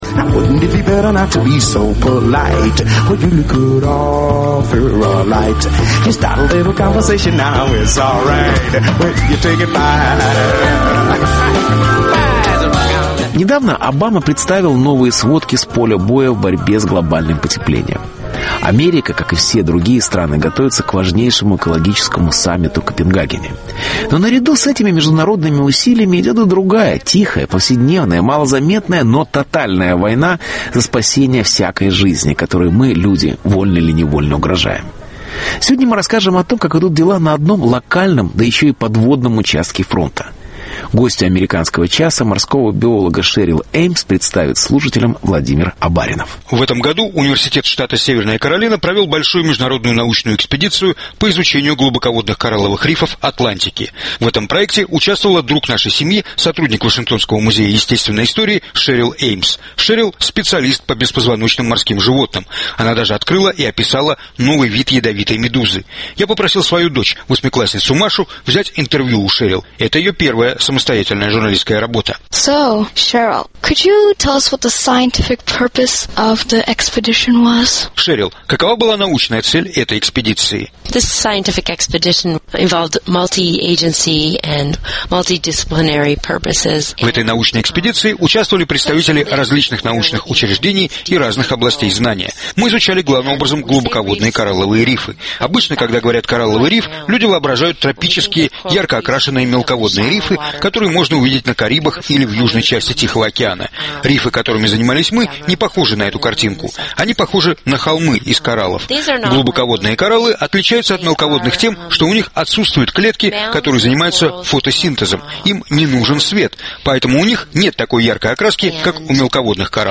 Интервью. Битва за кораллы.